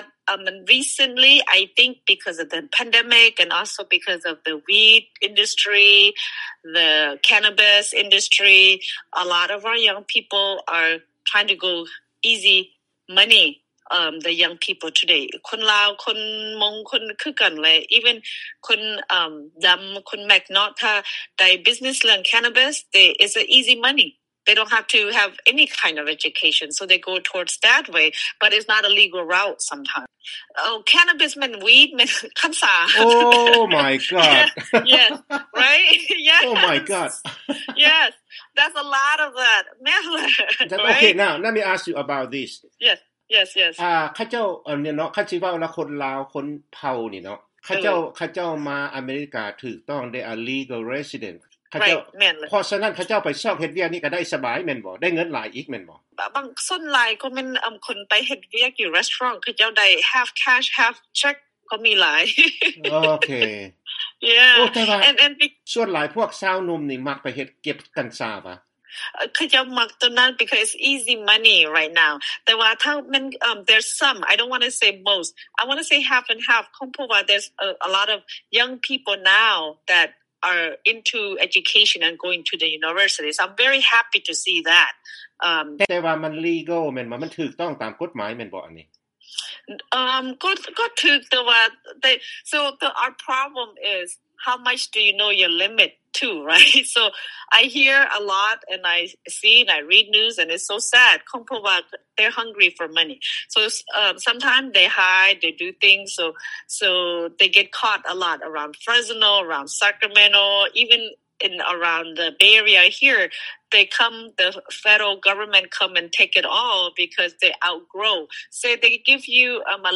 ເຊີນຟັງານສຳພາດ